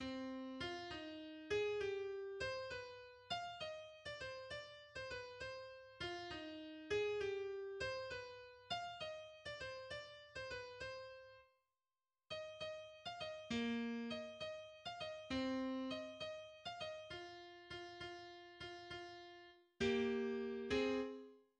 Genre Symphonie
, en ut mineur, 43 mesures, 2 sections répétées deux fois (mesures 1 à 19, mesures 20 à 43), les trompettes et les timbales ne jouent pas
Introduction de l'Andante :